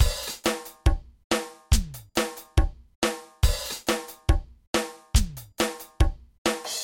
雷鬼鼓
Tag: 70 bpm Reggae Loops Drum Loops 1.15 MB wav Key : Unknown